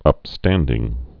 (ŭp-stăndĭng, ŭpstăn-)